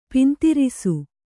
♪ pintirisu